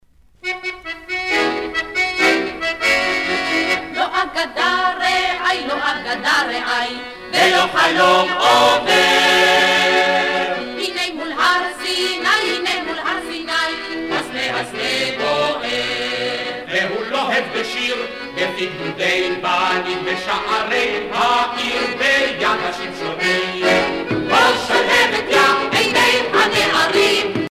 Chants patriotiques et de retour